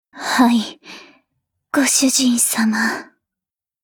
贡献 ） 协议：Copyright，人物： 碧蓝航线:奥古斯特·冯·帕塞瓦尔语音 您不可以覆盖此文件。